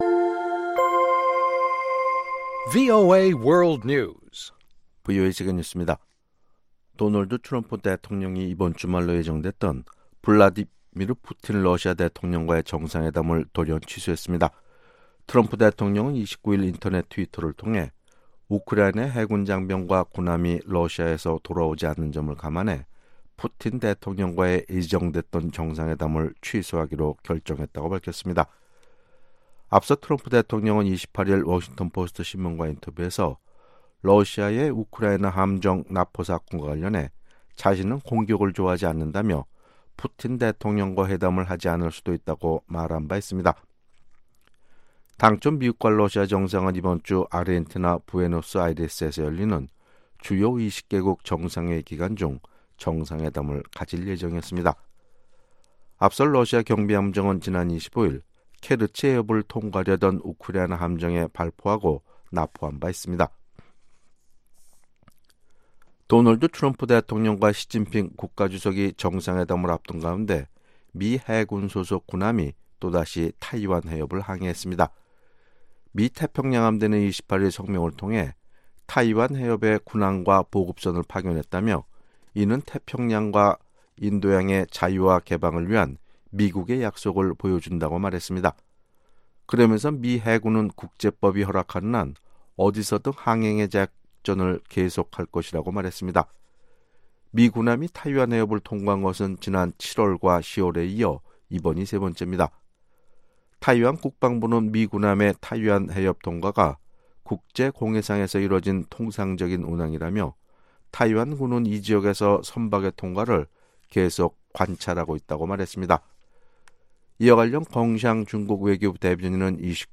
VOA 한국어 아침 뉴스 프로그램 '워싱턴 뉴스 광장' 2018년 11월 30일 방송입니다. 미 국무부가 북한의 풍계리 핵실험장 폐쇄 여부를 검증할 수 있어야 한다는 입장을 거듭 밝혔습니다. 미국 외희에서 북한 김정은 위원장이 약속한 비핵화에 진전이 없다면서 의회 차원의 강력한 조치를 취하려는 움직임이 나왔습니다.